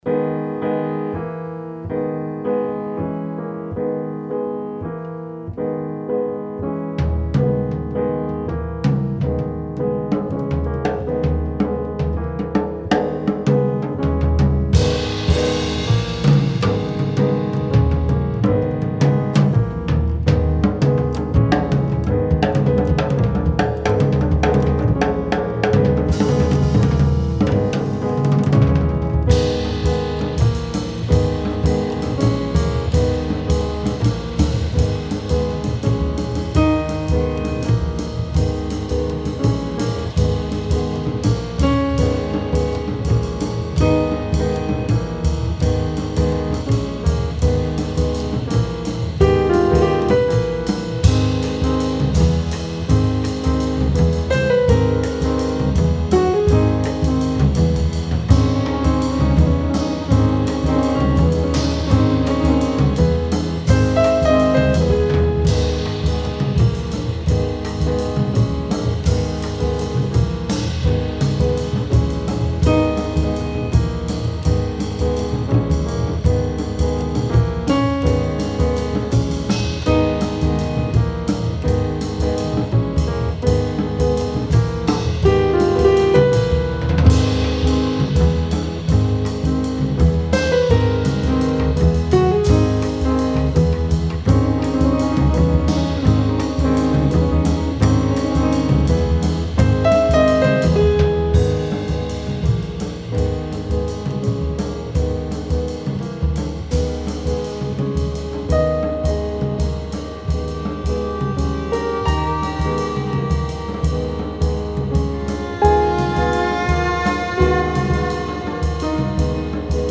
sax soprano, clavinet, laptop, voce
pianoforte
batteria, congas voce
voce recitante, percussioni e altro